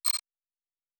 pgs/Assets/Audio/Sci-Fi Sounds/Interface/Error 20.wav at master
Error 20.wav